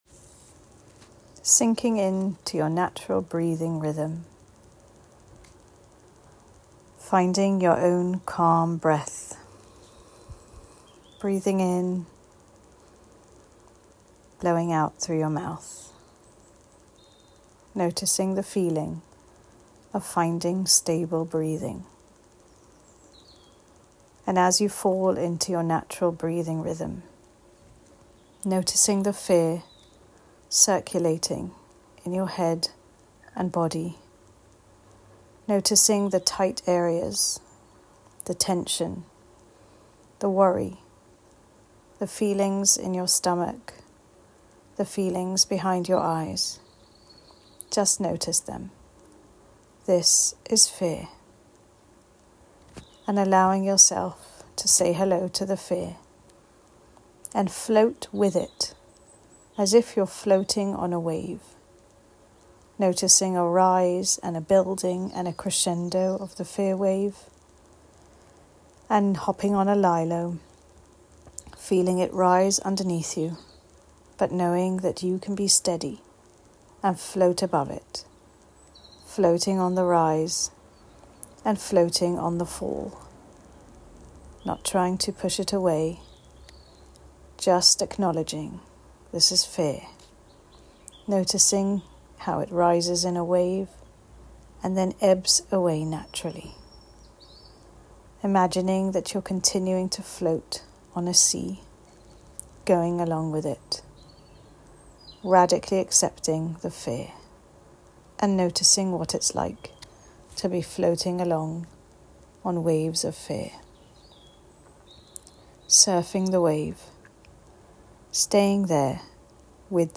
Enjoy and feel free to share TorahPsych’s collection of breathing and visualisation exercises to help you calmly tolerate feelings.